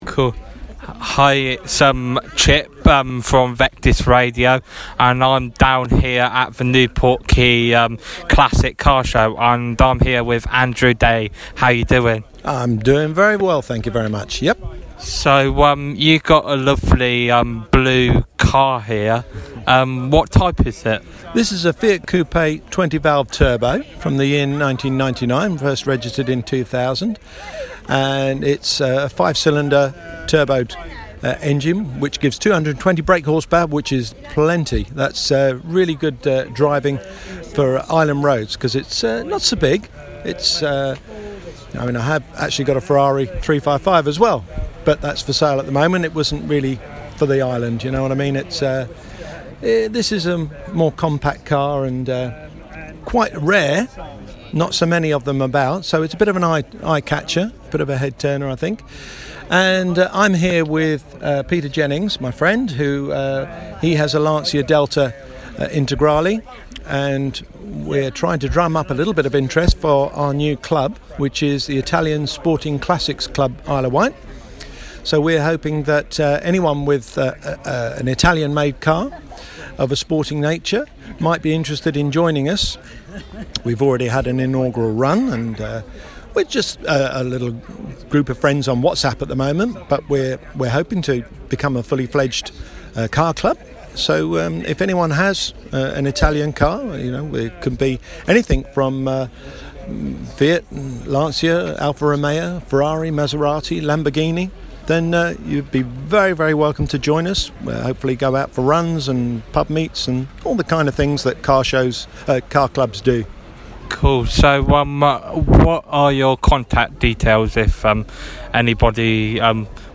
Interview Classic Car Show